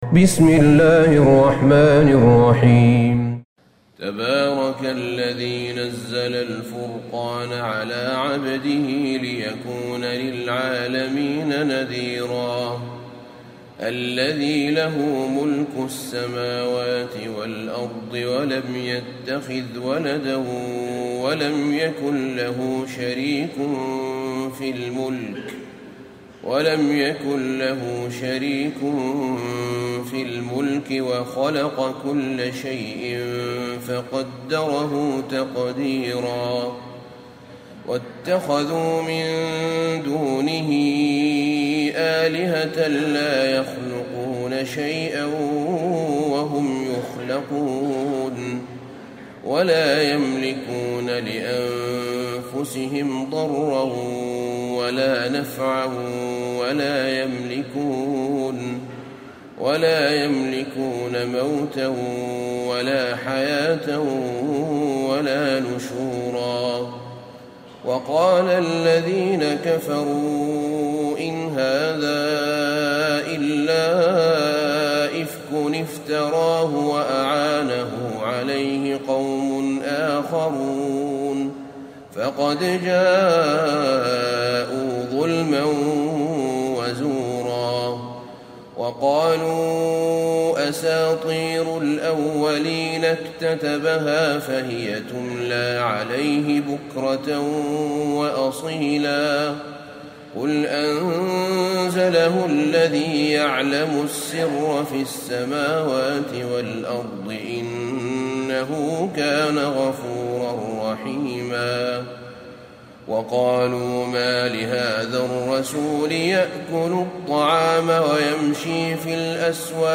سورة الفرقان Surat Al-Furqan > مصحف الشيخ أحمد بن طالب بن حميد من الحرم النبوي > المصحف - تلاوات الحرمين